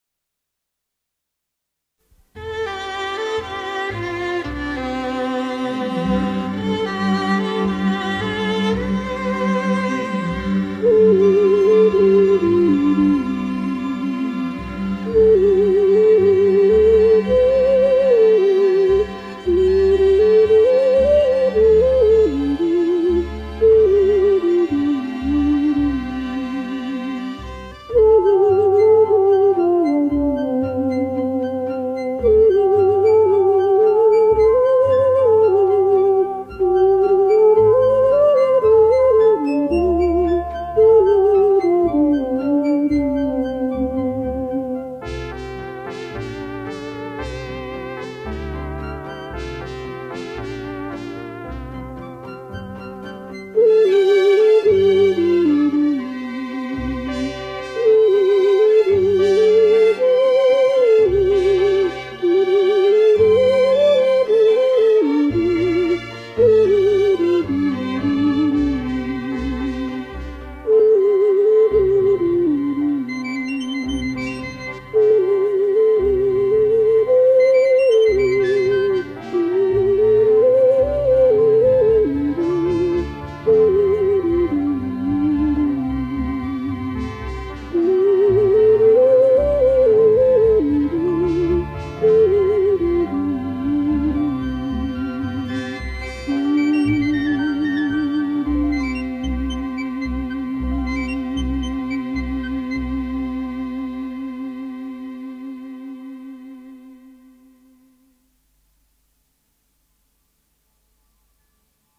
0112_走西口 埙演奏.mp3